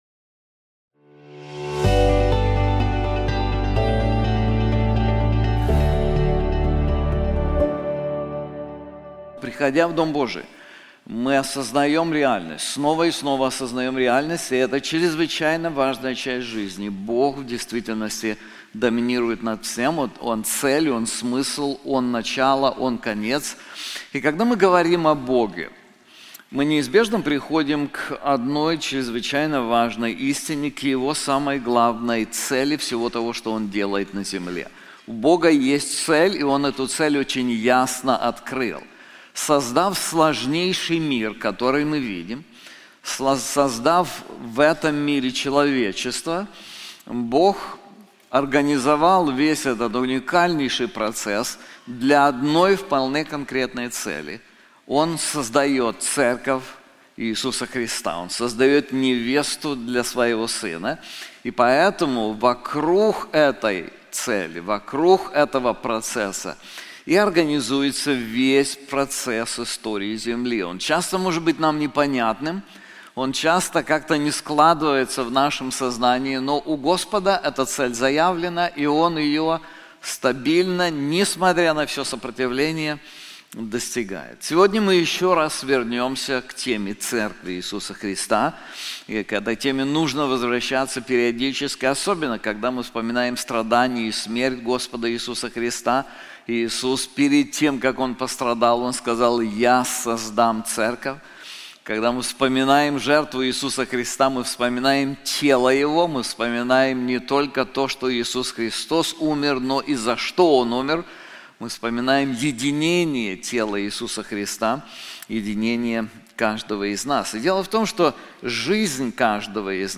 This sermon is also available in English:Diakonia in the Life of the Church • Selected Scriptures